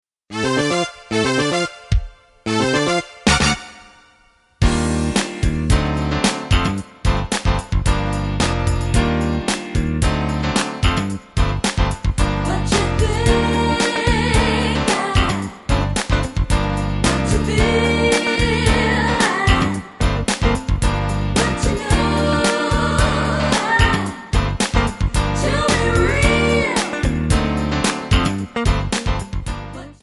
G/A
MPEG 1 Layer 3 (Stereo)
Backing track Karaoke
Pop, Disco, 1970s